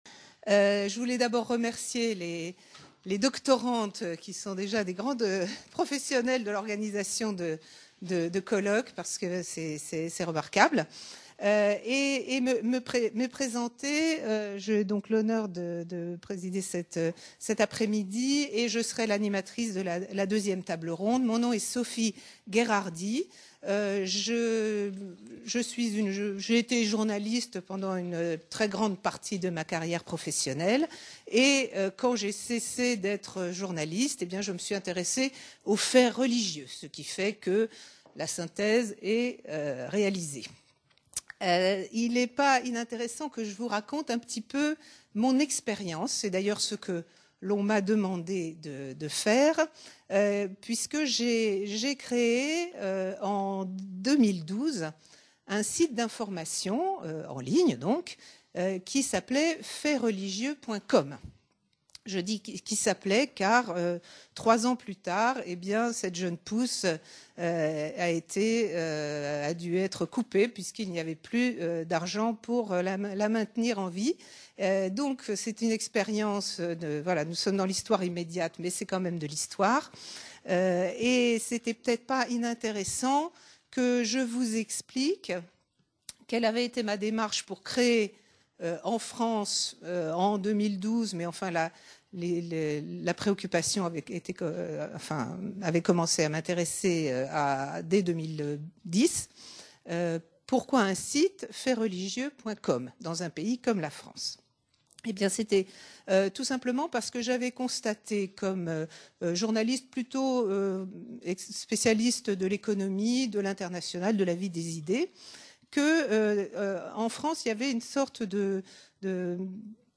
Première table-ronde : Pratiques et déontologie journalistiques à l’épreuve des faits religieux | Canal U